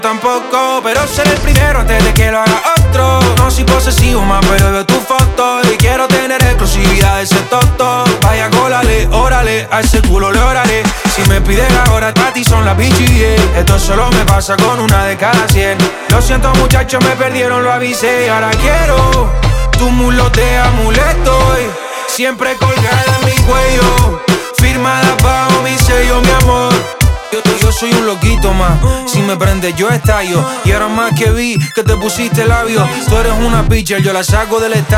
Жанр: Латино
Urbano latino